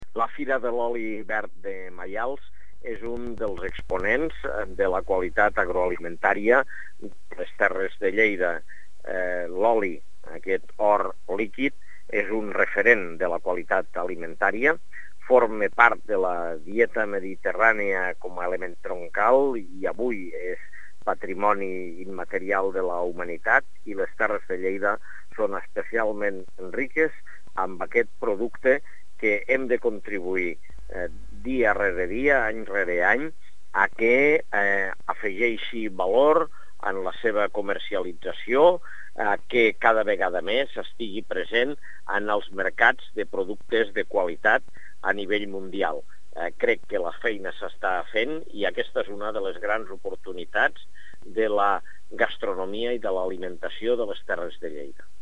tall-de-veu-de-lalcalde-angel-ros-sobre-la-seva-visita-a-la-xvi-fira-de-loli-verd-de-maials